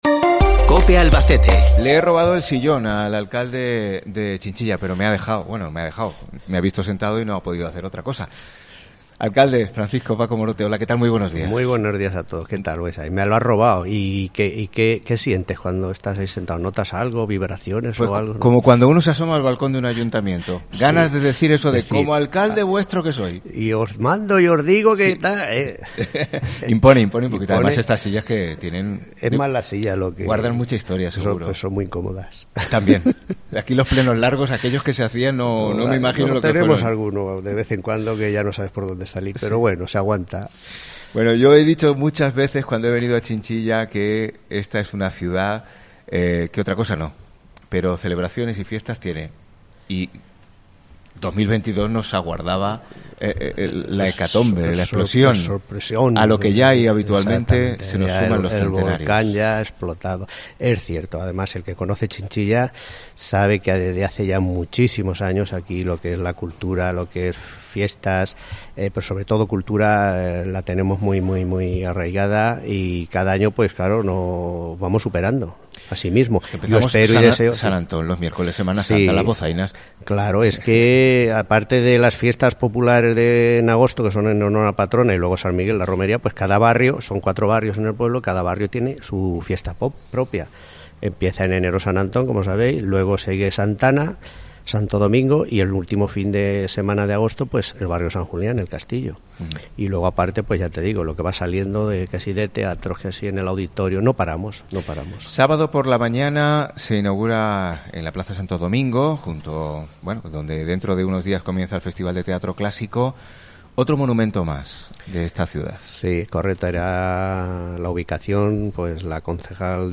Desde el salon de plenos de este ayuntamiento junto al alcalde Francisco Morote